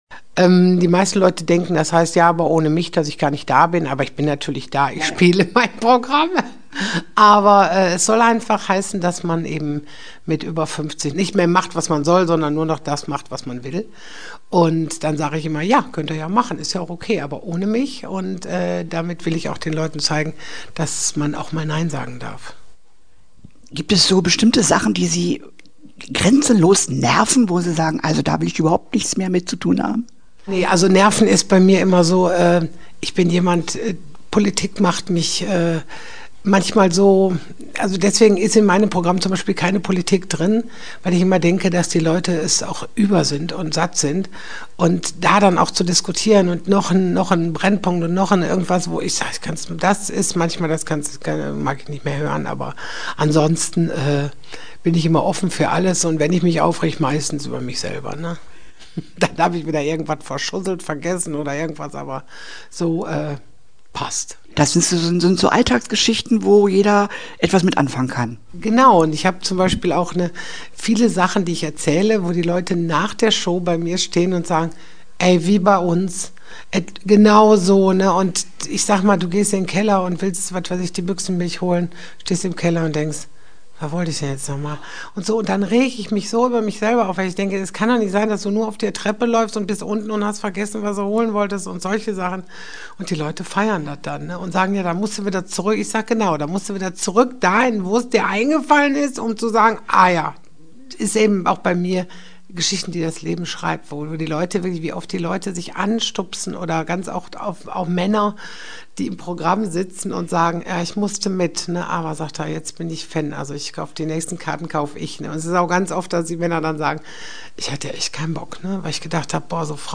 Interview-Ingrid-Kuehne.mp3